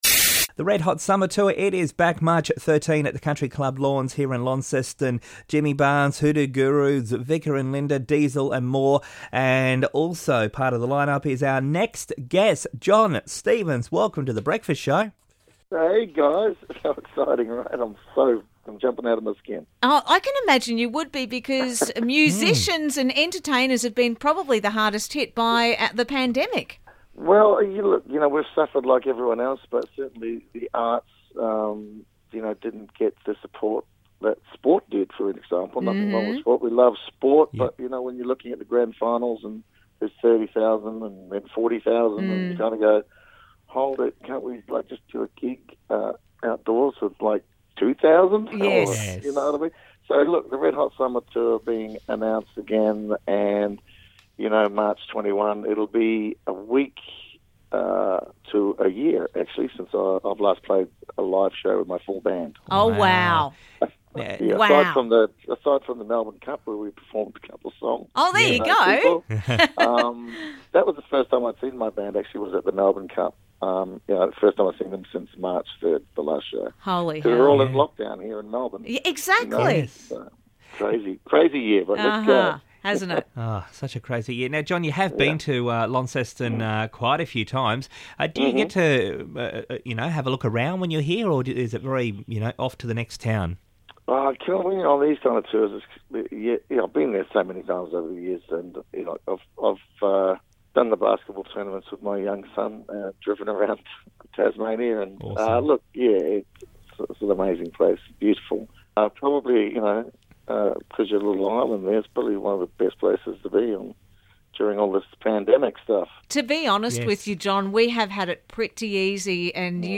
Interview - Jon Stevens Part 1